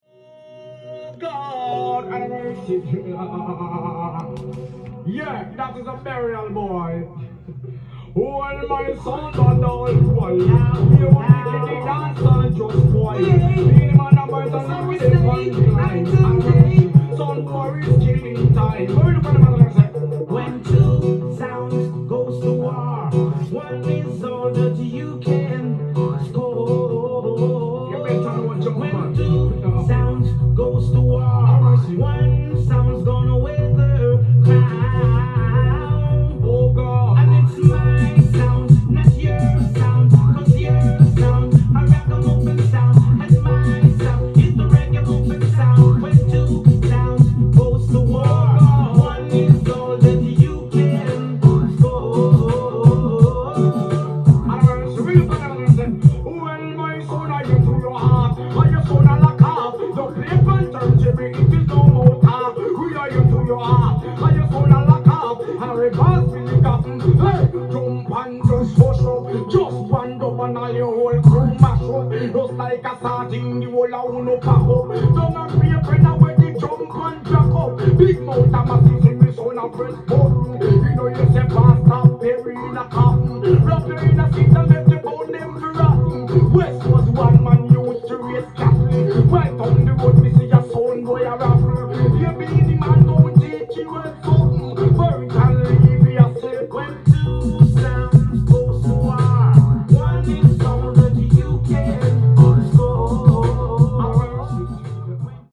ジャンル：REGGAE
店頭で録音した音源の為、多少の外部音や音質の悪さはございますが、サンプルとしてご視聴ください。